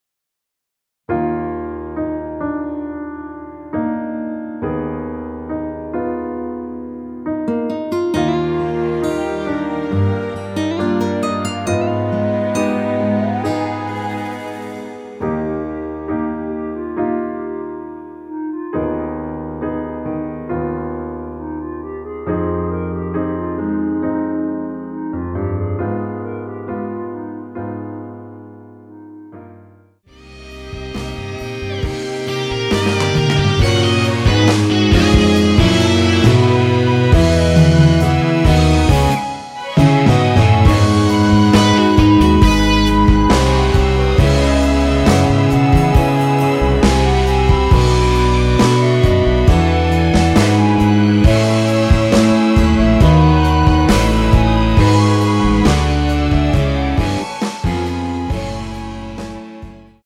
원키에서(+3)올린 멜로디 포함된 MR입니다.
Eb
앞부분30초, 뒷부분30초씩 편집해서 올려 드리고 있습니다.
중간에 음이 끈어지고 다시 나오는 이유는